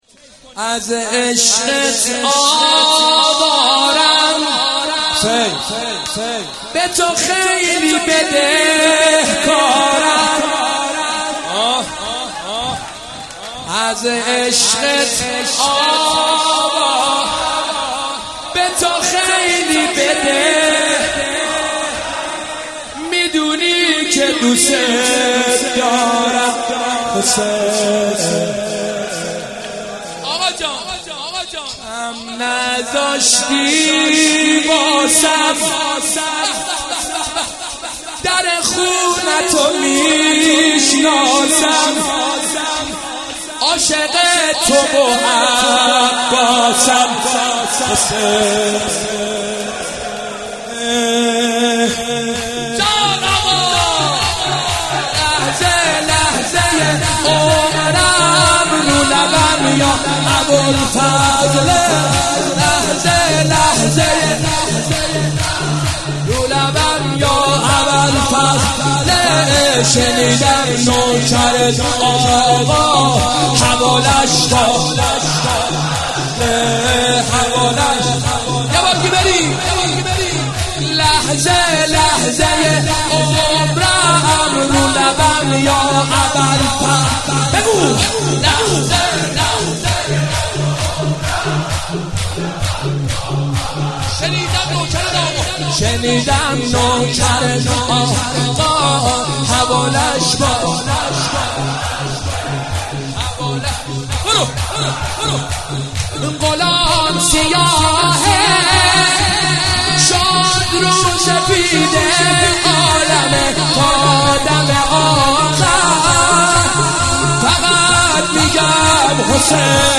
مداحی سیب سرخی شور